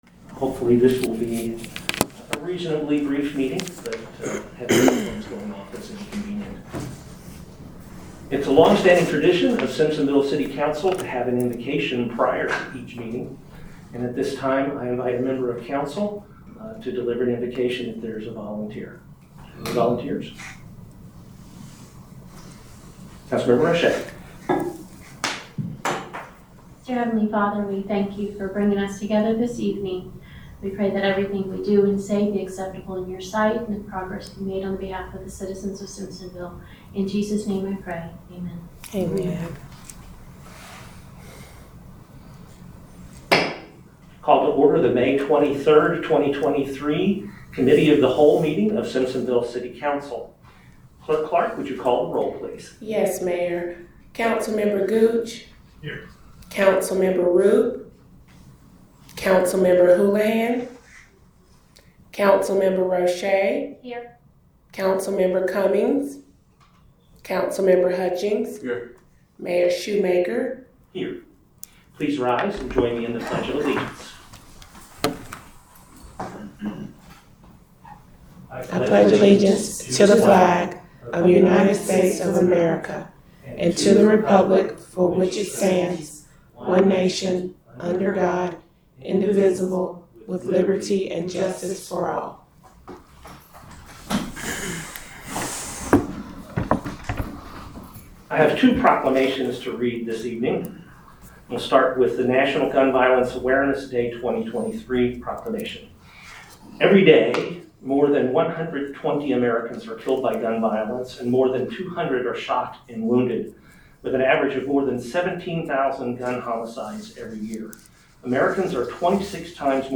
City Council Committee of the Whole Meeting